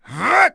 Zafir-Vox_Attack2.wav